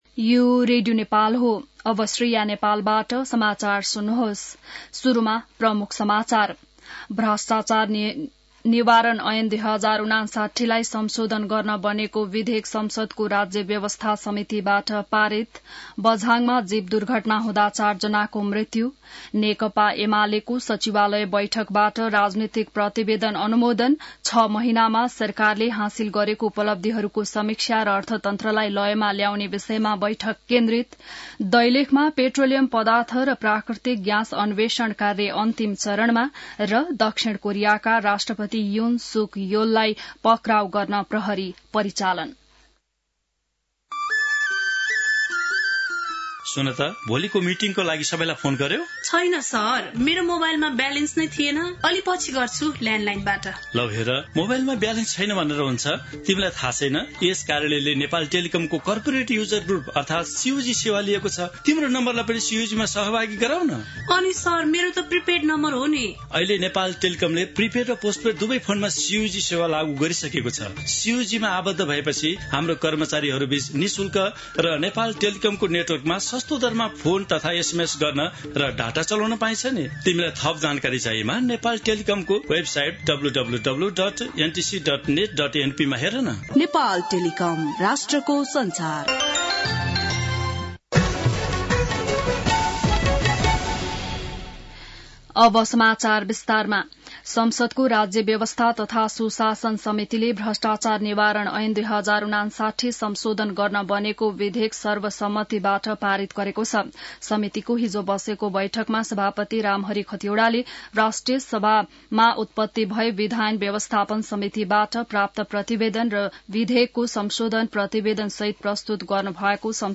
बिहान ७ बजेको नेपाली समाचार : २० पुष , २०८१